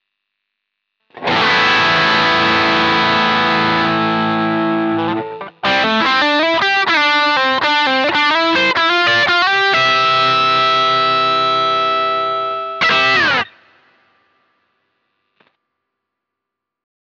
GT -1000coreとGX-1の同じアンプタイプにうっすらホールリバーブをかけた音でサンプルを録ってみました。
いずれもアンプシミュレーターとリバーブのみの音色です。
GT -1000core 歪み（アンプタイプ　X-HI GAIN）
GT-1000core-Drive.wav